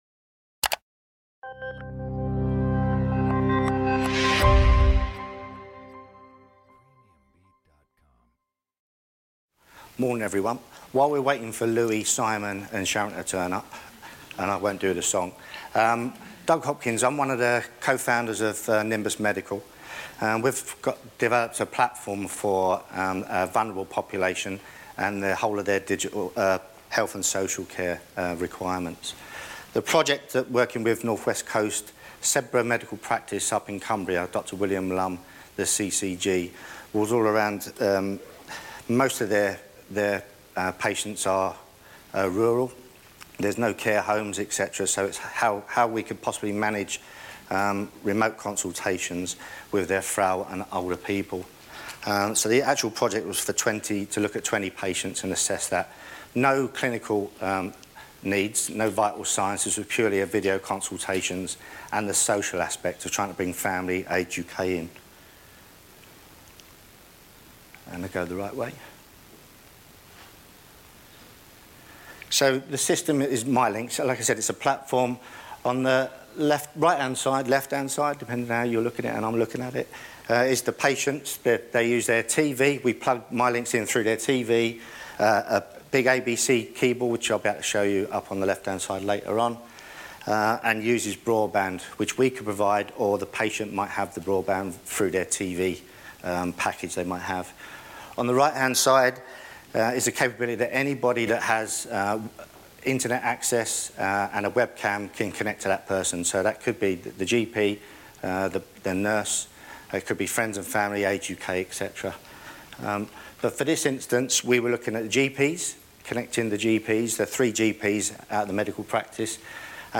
Recorded at Aintree Racecourse - 13/09/2016